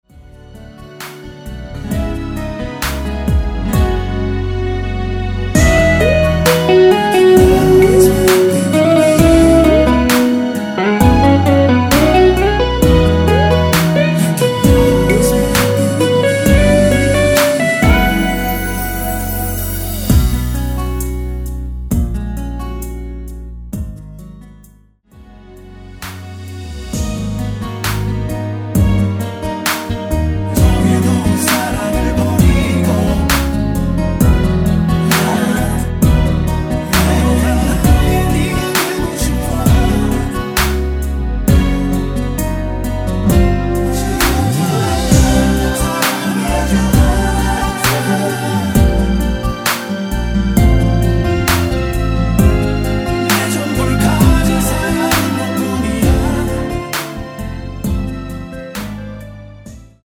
(-1)내린 코러스 포함된 MR 입니다.(미리듣기 참조)
◈ 곡명 옆 (-1)은 반음 내림, (+1)은 반음 올림 입니다.
앞부분30초, 뒷부분30초씩 편집해서 올려 드리고 있습니다.
중간에 음이 끈어지고 다시 나오는 이유는